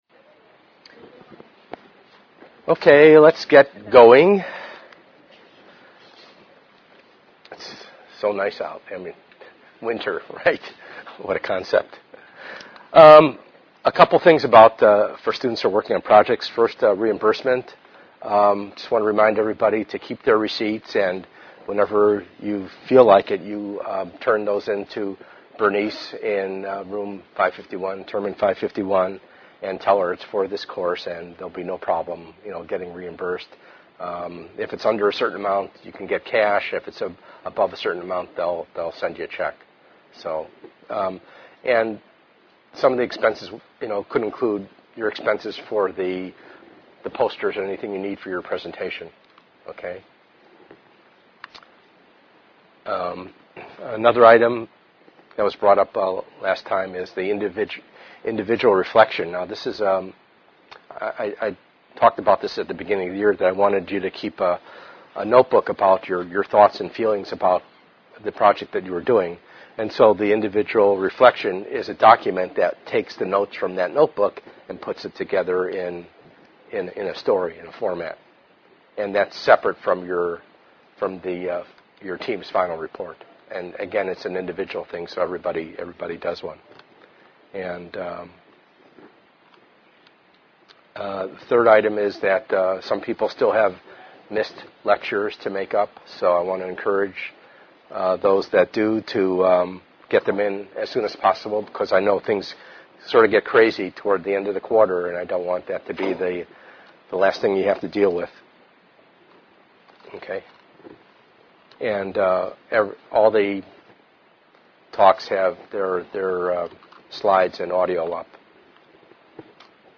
Abstract: This presentation will cover the following topics: